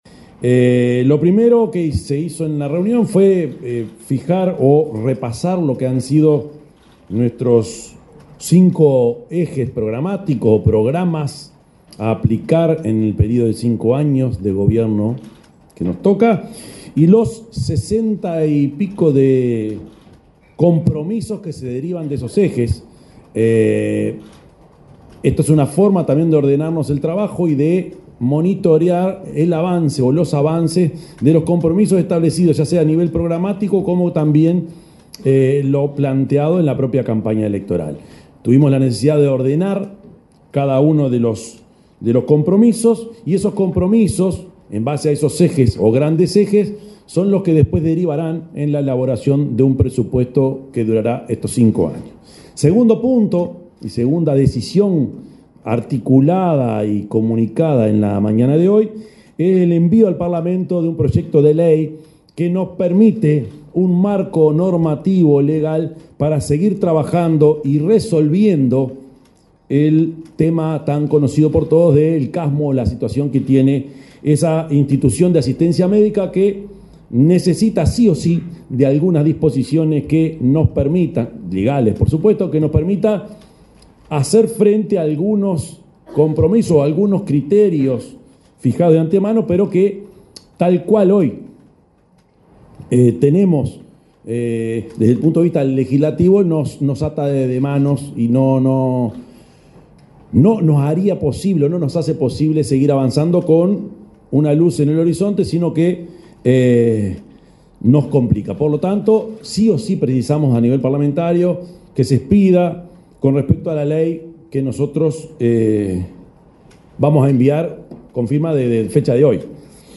El presidente de la República, profesor Yamandú Orsi, anunció que el Gobierno enviará este martes 25 un proyecto de ley de cuatro artículos, para, entre otras cosas, liberar los fondos del último tramo del fideicomiso, que permitirá continuar trabajando en soluciones para el Casmu.